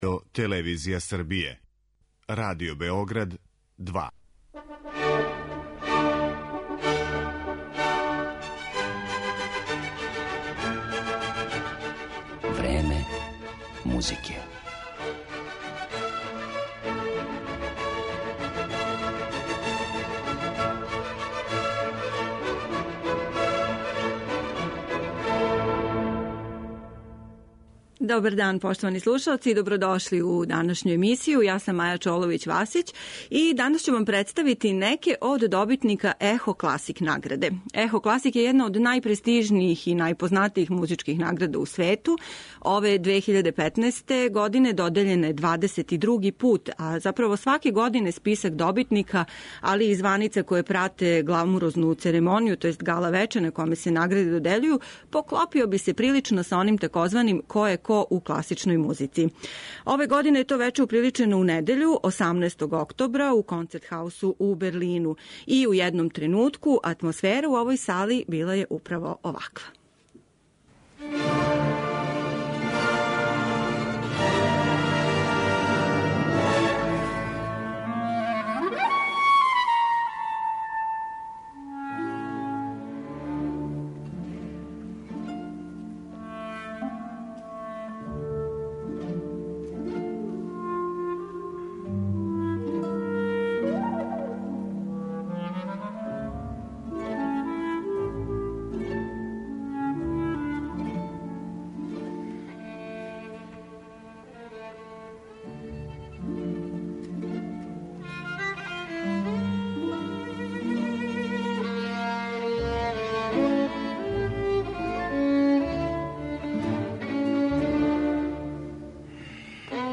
У данашњој емисији Време музике, представићемо вам неке од награђених, као и њихове најновије снимке, а бићете у прилици да чујете и део атмосфере са свечане церемоније.